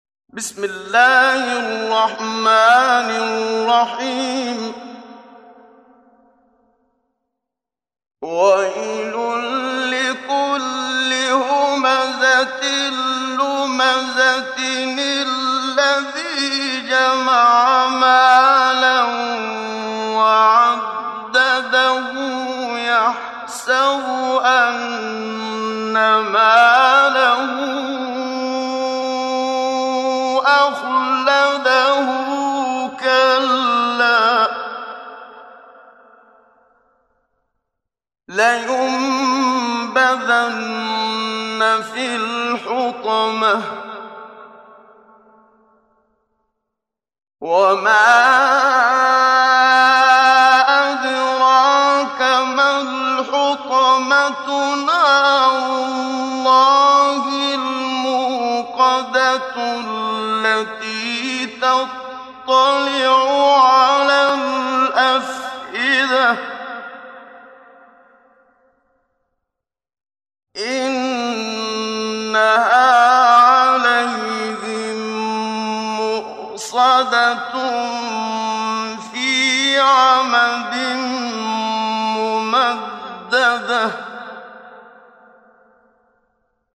محمد صديق المنشاوي – تجويد – الصفحة 9 – دعاة خير